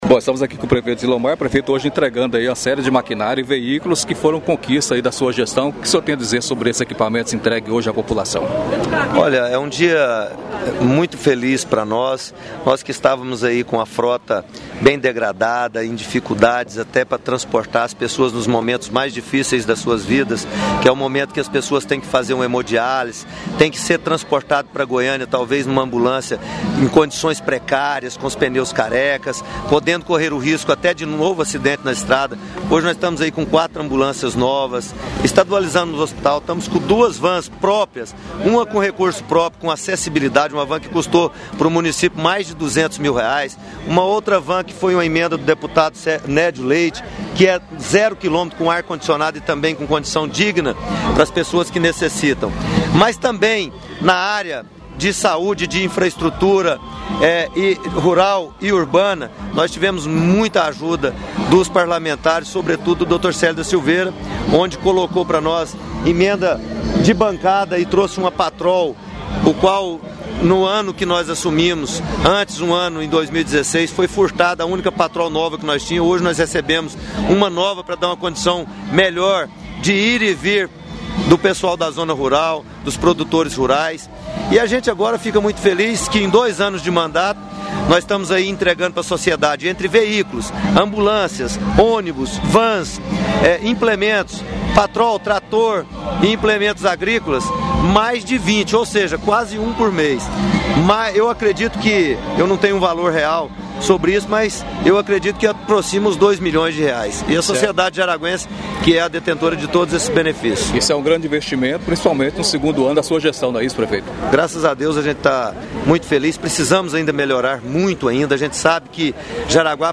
Ouça a entrevista com o prefeito Zilomar